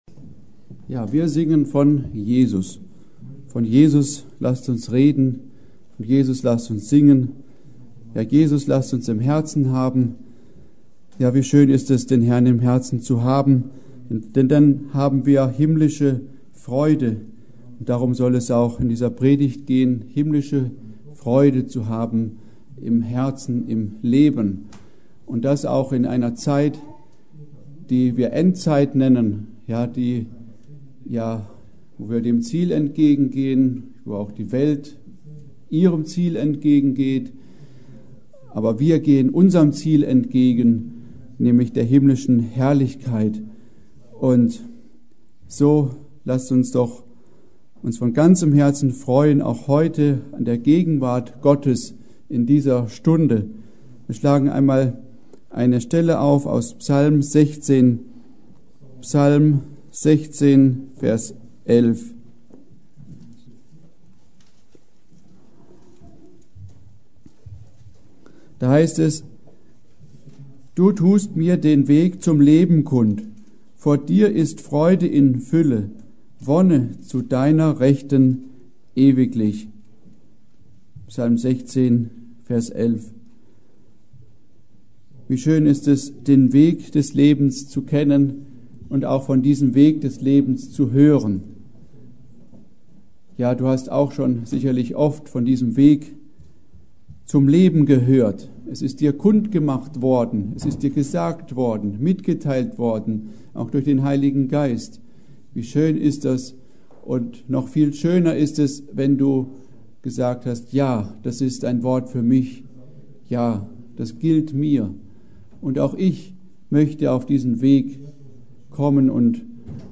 Juli 2023 Predigt